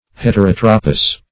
Search Result for " heterotropous" : The Collaborative International Dictionary of English v.0.48: Heterotropal \Het`er*ot"ro*pal\, Heterotropous \Het`er*ot"ro*pous\, a. [Gr.